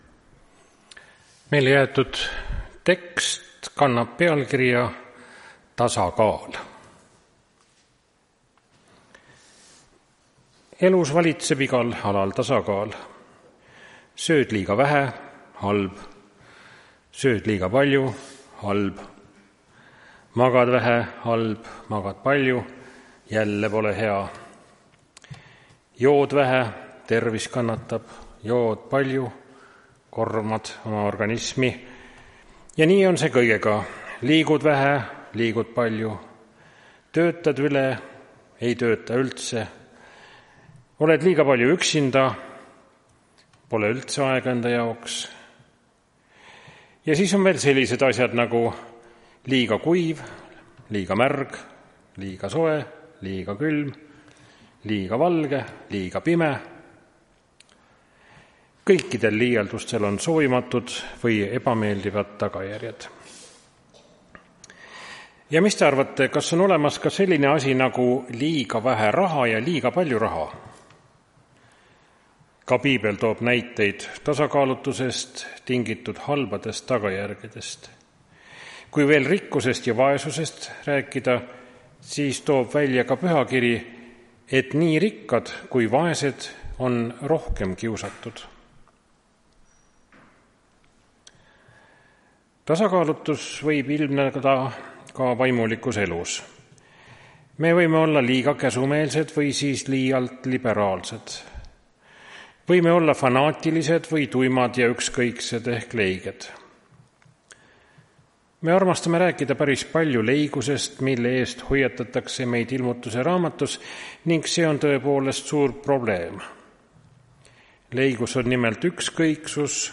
Tartu adventkoguduse 02.08.2025 hommikuse teenistuse jutluse helisalvestis.